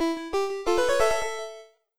LevelUp2.wav